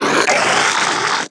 zling_bat2.wav